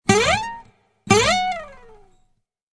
Descarga de Sonidos mp3 Gratis: guitarra 25.